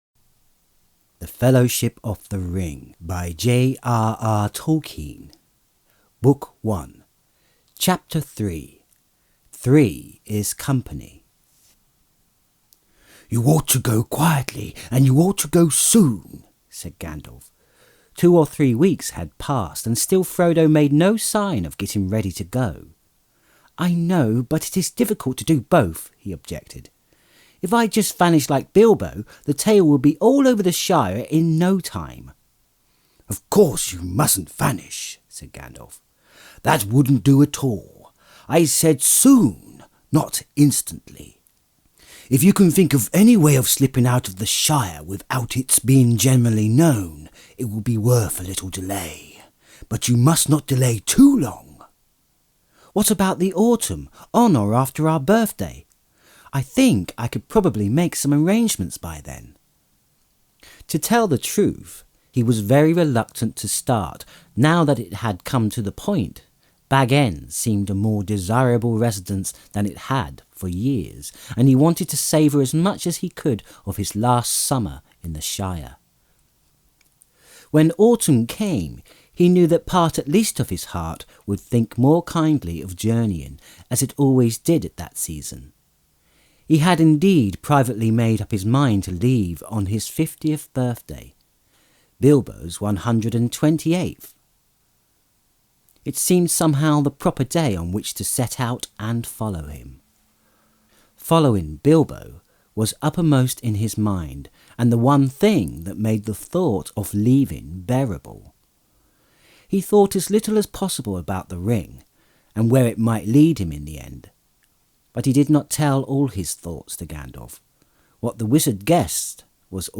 Lord Of The Rings And Hobbit Audiobooks (J.R.R. Tolkien)! Podcast - The Fellowship of the Ring - Book One | Chapter 3: Three Is Company (J.R.R. Tolkien) | Free Listening on Podbean App